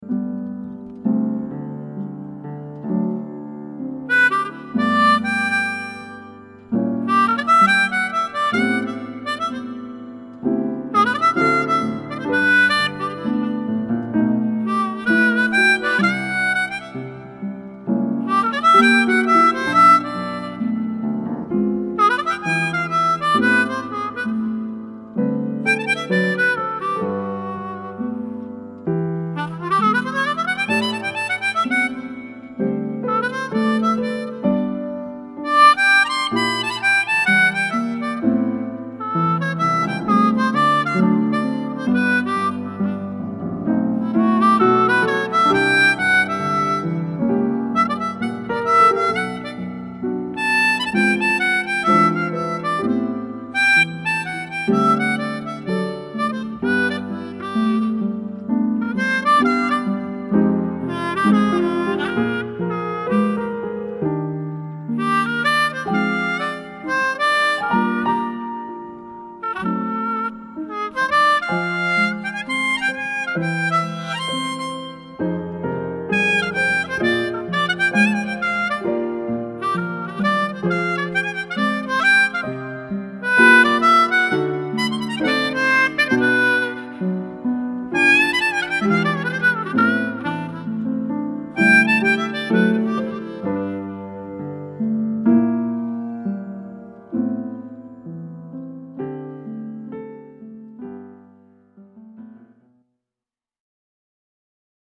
オーバーブロウ無しよ